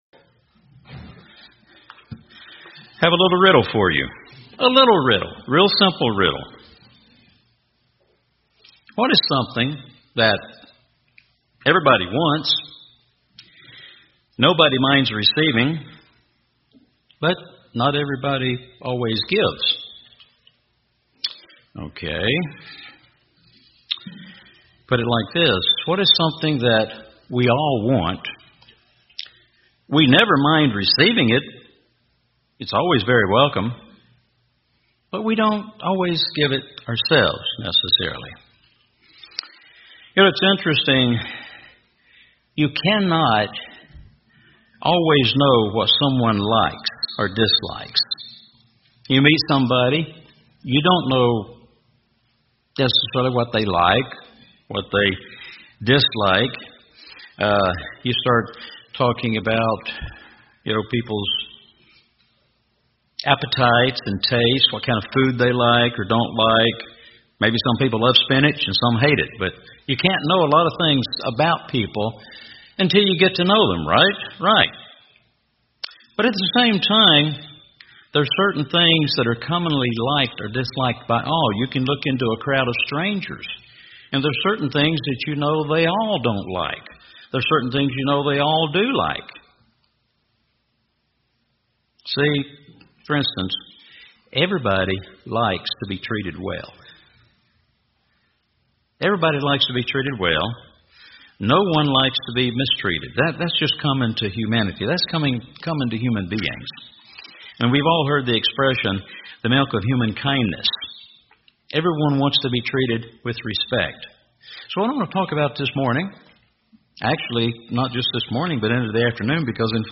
I could just go on and on about the contents of this wonderful sermon.